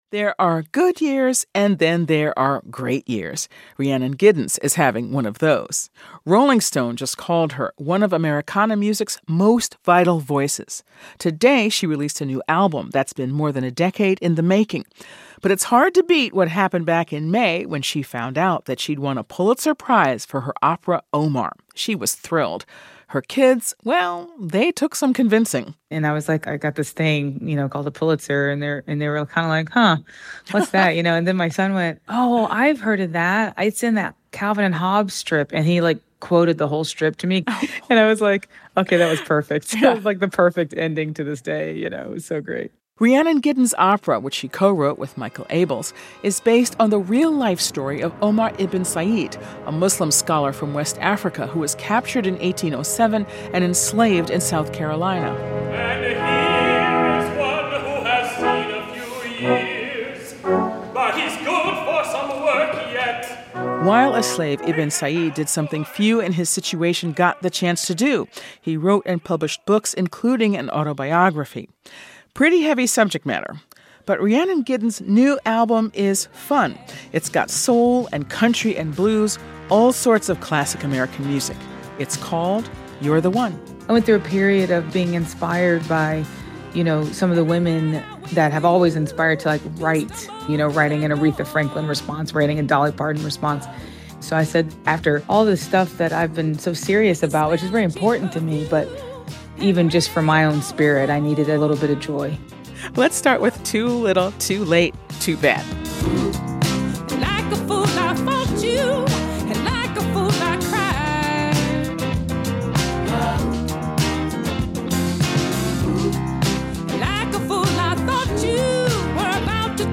In this interview with NPR's Michel Martin, she reflects on her new album and more.